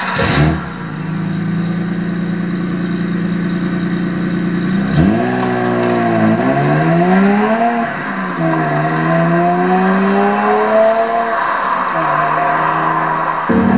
Sons de moteurs bmw - Engine sounds bmw - bruit V8 V10 bmw